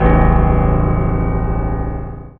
55y-pno04-e2.wav